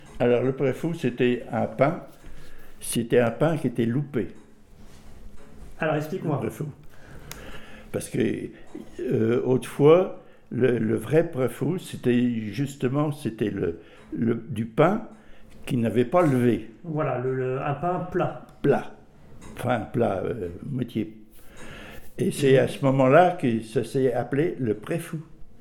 Enquête autour du préfou
Catégorie Témoignage